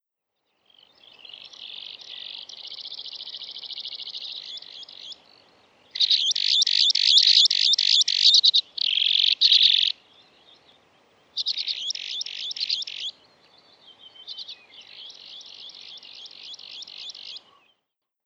【分類】 チドリ目 シギ科 オバシギ属 キリアイ 【分布】北海道(旅鳥)、本州(旅鳥)、四国(旅鳥)、九州(旅鳥)、沖縄(旅鳥) 【生息環境】干潟、河口部、水田に生息 【全長】17cm 【主な食べ物】ゴカイ、昆虫 【鳴き声】地鳴き 【聞きなし】「チョイチョイチョイ」「チョチョチリリリ」